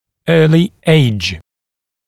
[‘ɜːlɪ eɪʤ][‘ё:ли эйдж]ранний возраст